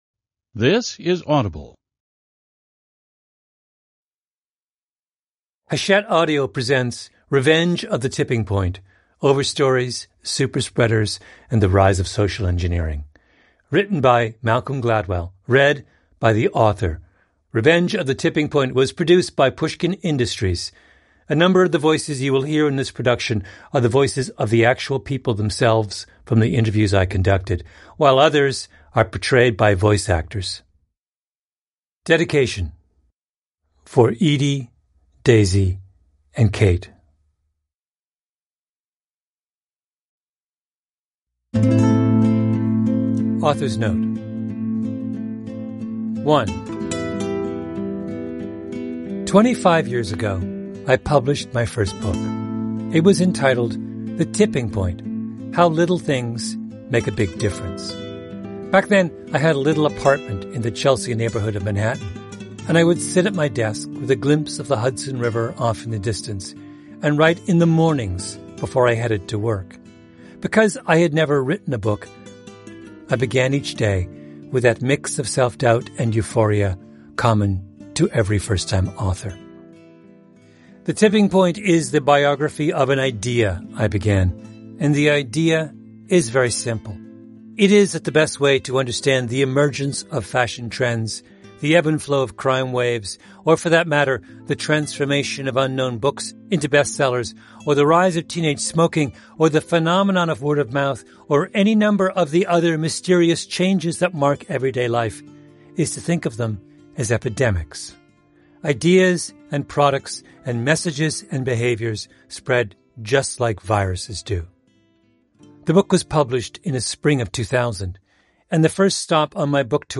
این اثر همچنین در قالبی صوتی ارائه شده و شنونده را به سفری درون داستان‌هایی جذاب و متنوع می‌برد، از سارقان بانک لس‌آنجلس گرفته تا بررسی اپیدمی‌های بزرگ معاصر مانند کووید-۱۹ و بحران مواد افیونی. گلدول با تلفیق روایت‌های گیرا، تاریخ شفاهی و موسیقی متن سینمایی، یک تجربه شنیداری فراموش‌نشدنی خلق کرده است.